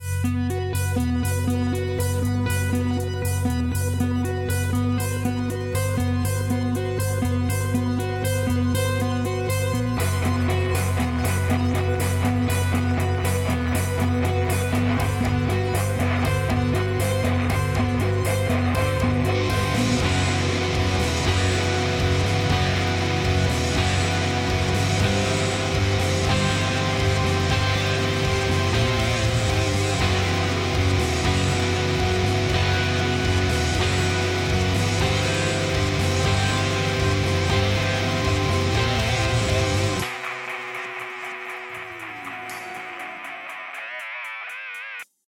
Вниз  Играем на гитаре
Как-то с года пол назад нашел на Guitar Rig крутой пресет.
Записал демку :-D Не помню, выкладывал сюда ее, или нет.